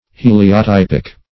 Heliotypic \He`li*o*typ"ic\